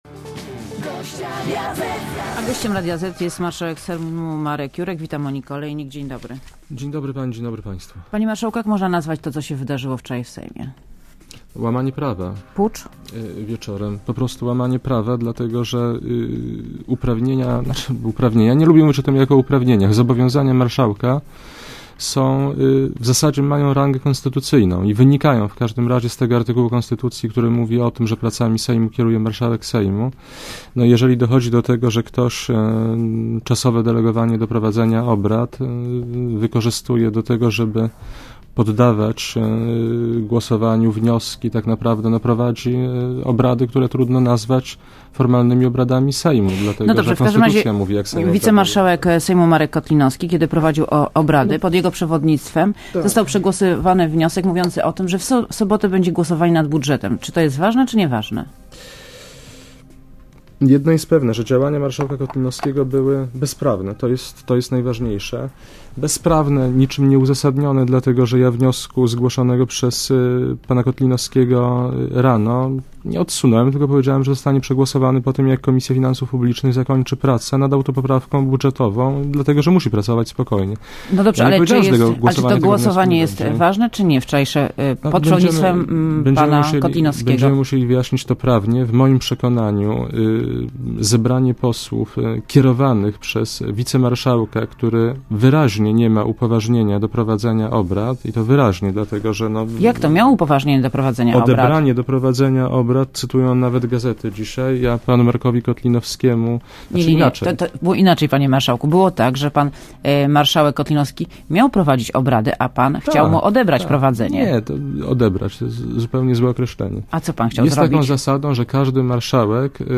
© (RadioZet) Posłuchaj wywiadu W środę wieczorem w Sejmie wspólnymi siłami opozycja doprowadziła do przyjęcia wniosku, który otwiera drogę do przeprowadzenia głosowania nad ustawą budżetową w najbliższą sobotę.